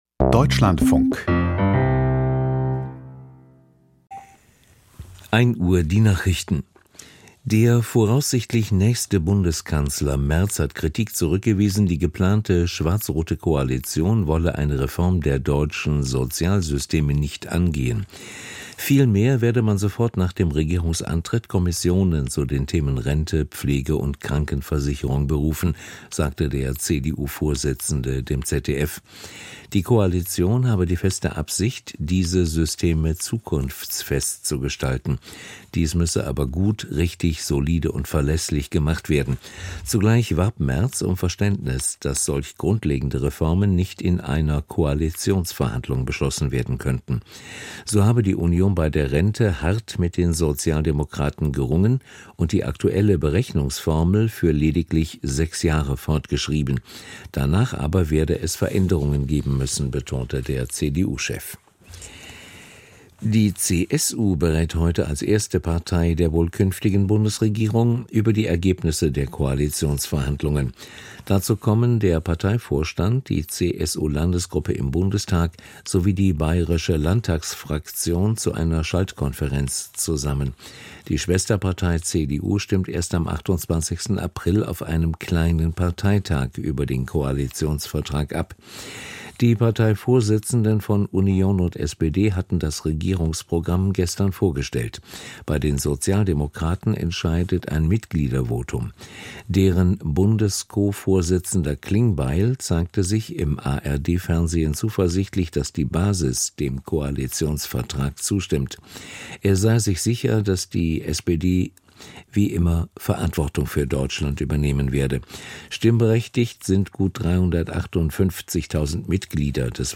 Die Deutschlandfunk-Nachrichten vom 10.04.2025, 01:00 Uhr